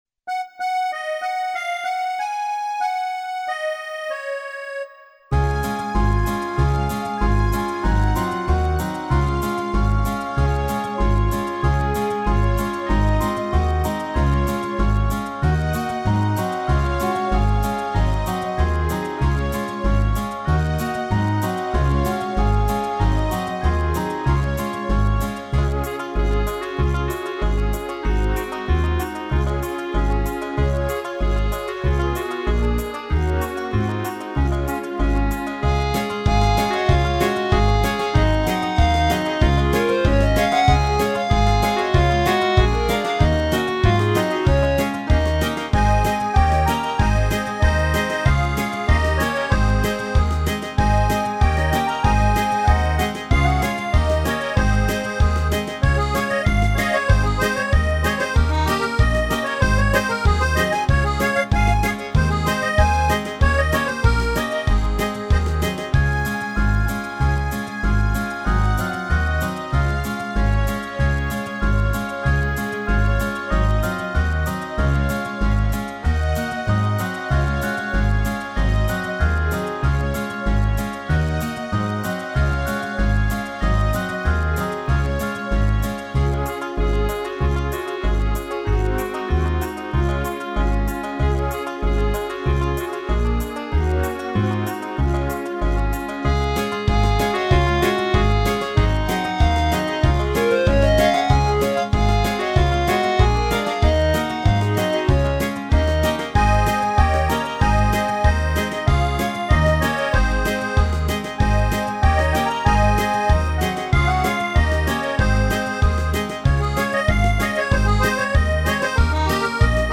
фонограмму (минус) детской песни